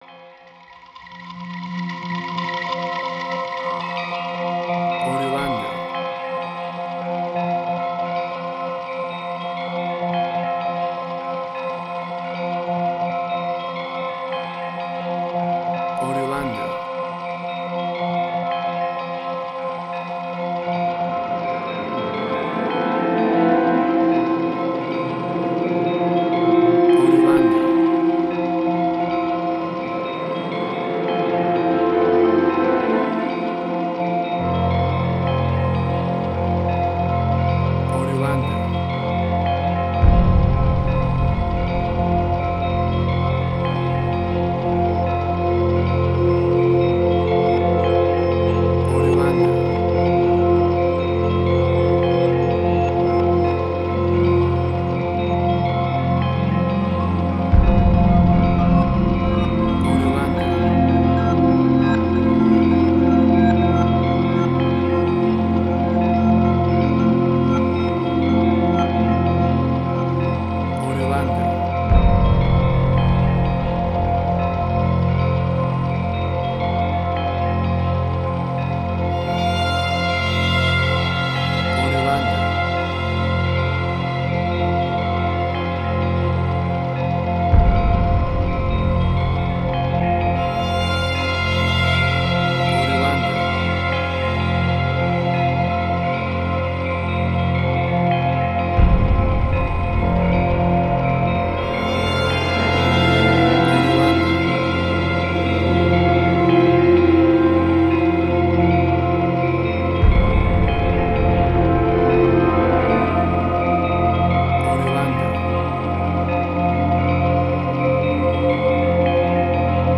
Indian Fusion.
emotional music